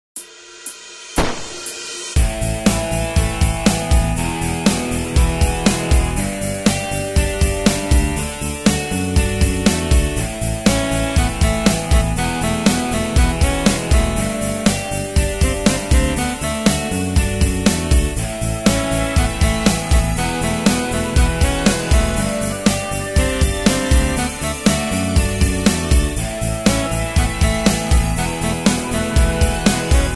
Tempo: 120 BPM.
MP3 with melody DEMO 30s (0.5 MB)zdarma